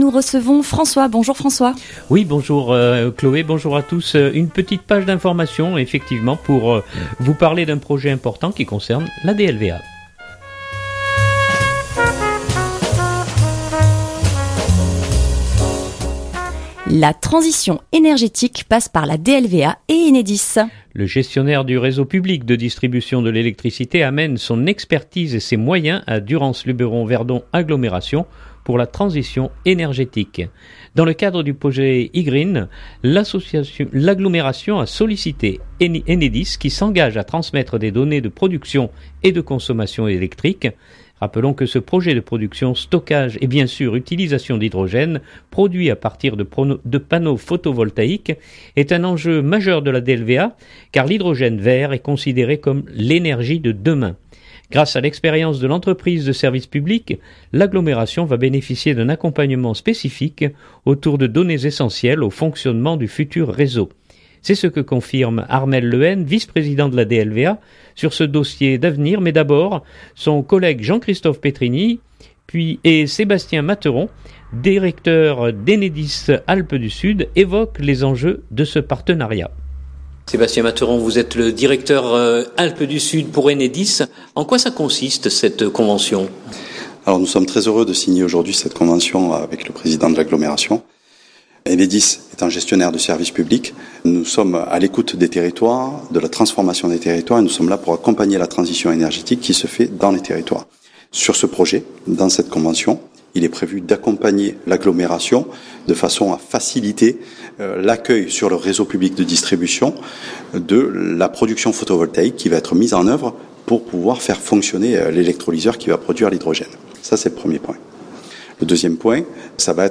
reportage enedis-Matinale du 2019-07-31.mp3 (4.98 Mo)